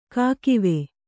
♪ kākive